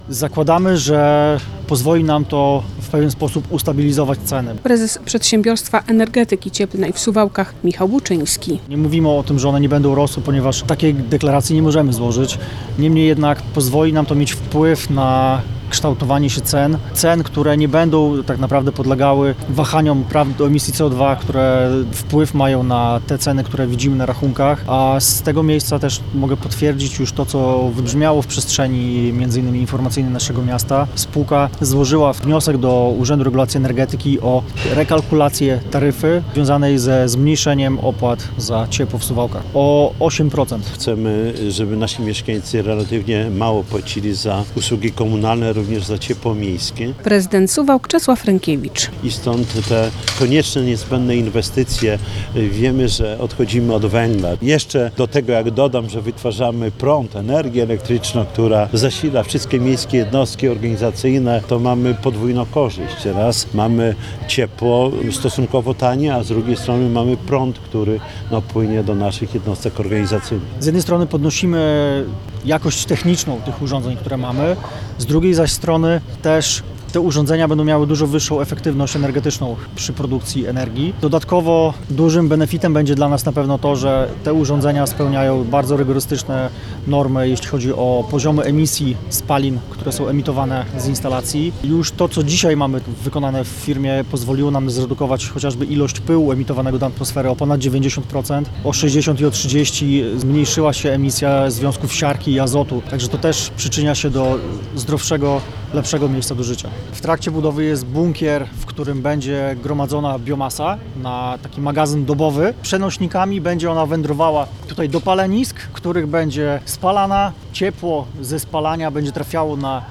relacja
Koszt tej instalacji to 146 milionów złotych. Dzięki temu miasto odejdzie od węgla, a kotły pozwolą na utrzymanie niskich cen za ciepło - podkreśla prezydent Suwałk Czesław Renkiewicz.